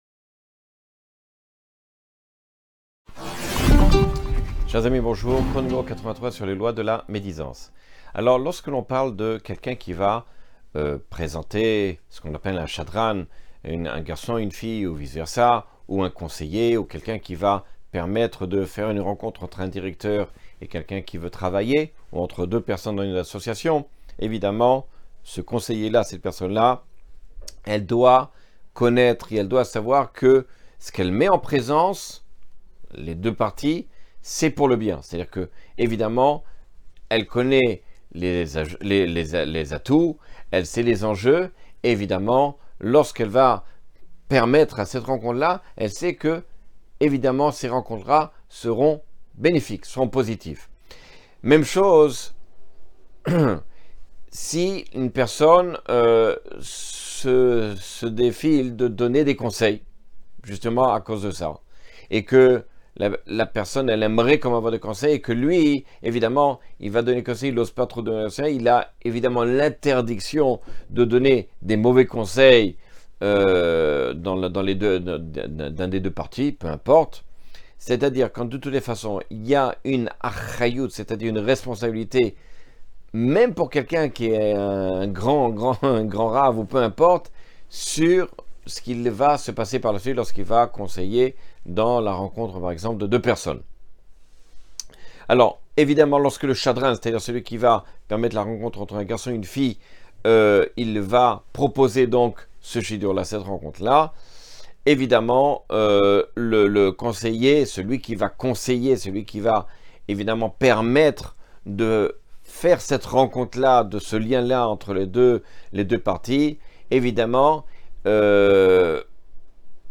Cours 83 sur les lois du lashon hara.